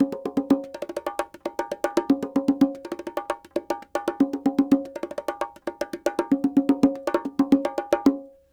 44 Bongo 05.wav